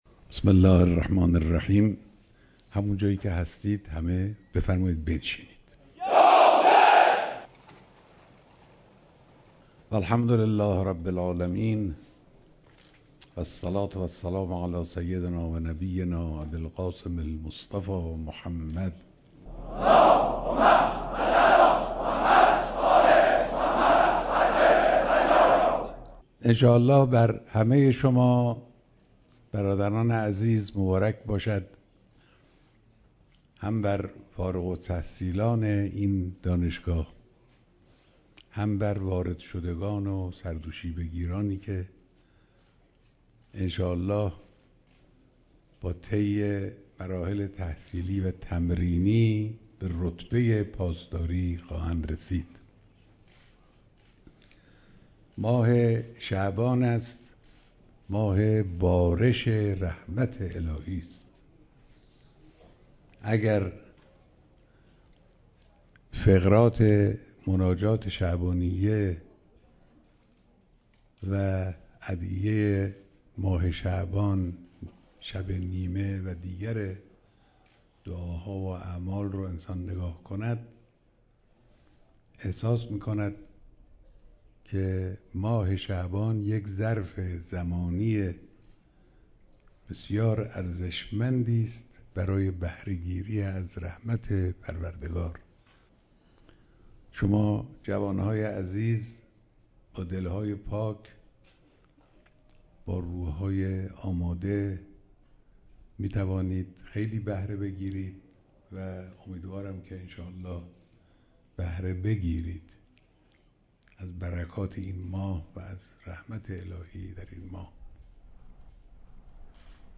بیانات در مراسم دانش‌آموختگی دانشگاه افسری و تربیت پاسداری امام حسین(ع)